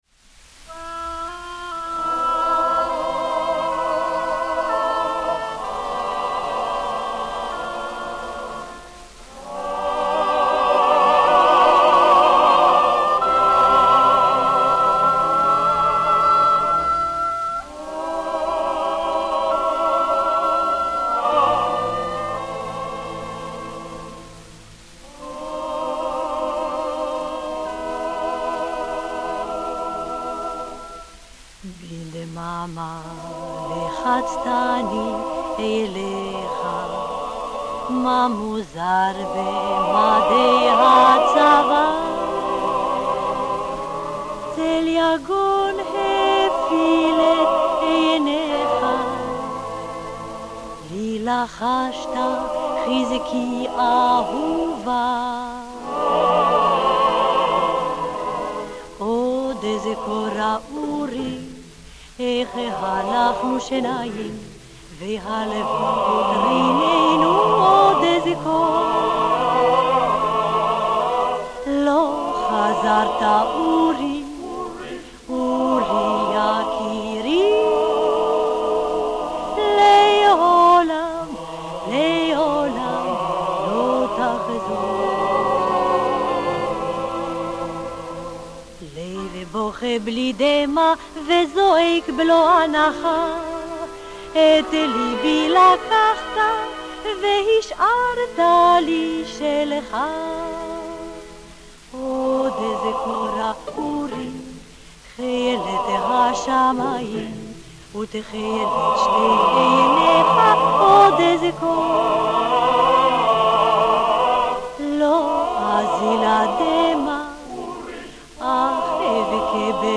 chanteuse israélienne